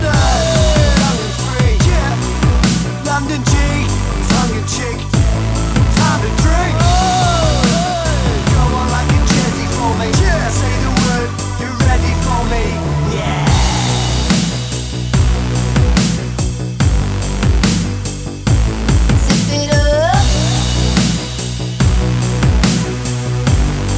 Duet Version R'n'B / Hip Hop 3:55 Buy £1.50